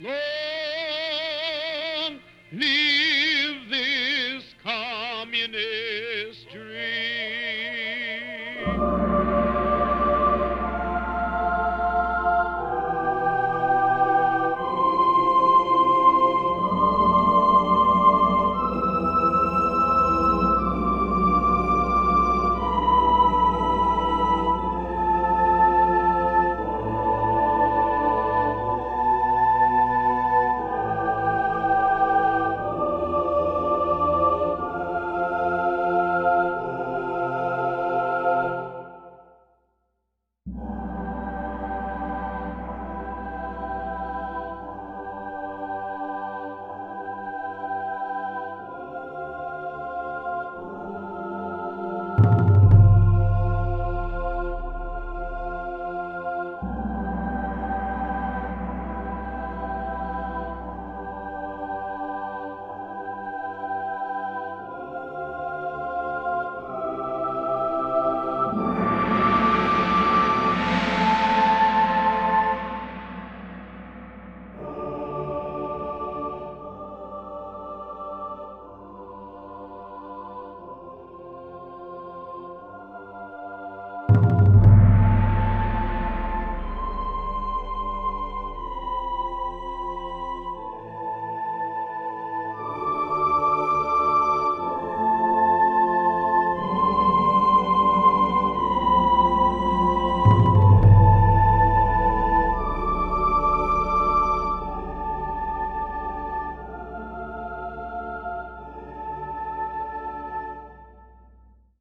Scored for massed voices and orchestral percussion, the sketch is from 2008.
Choral-montage.mp3